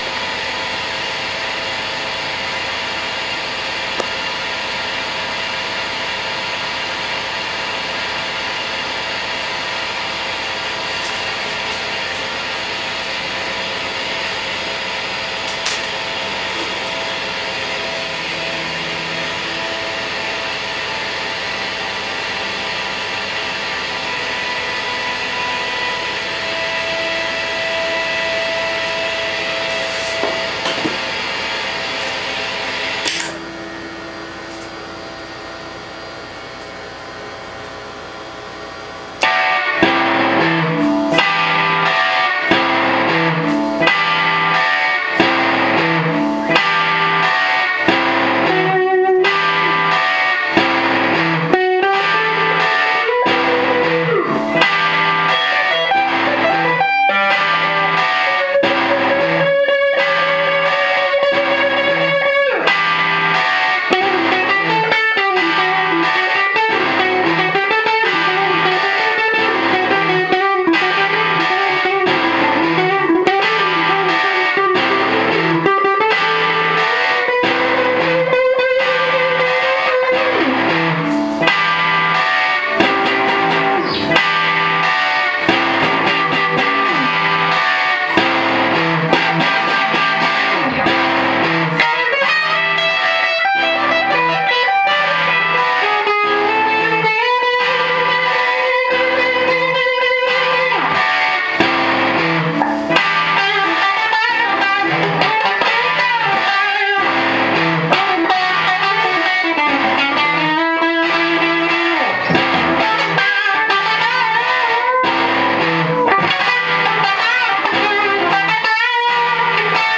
Decided for the first time tonight that I ought to whip out my digital camera and see how it's onboard mic would handle some guitar sounds.
It's frantic, repetitive & not very well recorded.
Oh, for those who care, it's my Tele into the Fuzz Face into a DL4 into my HIWATT. At the beginning & end I step on my tuner so's ya' get a little taste of the radio interference.